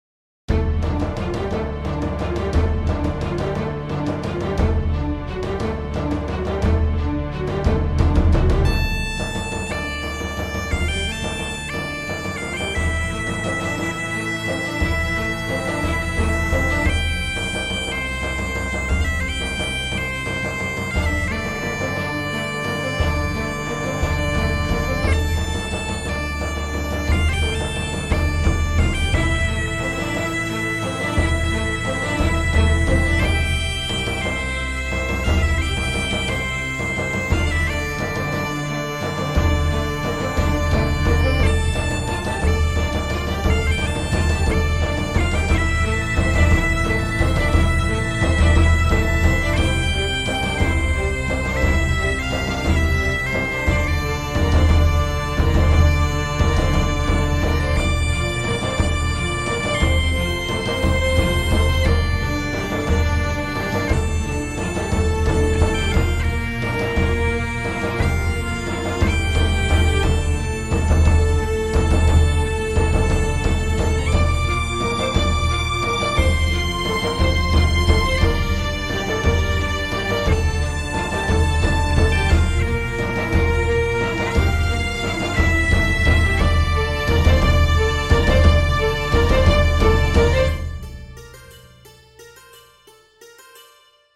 на волынке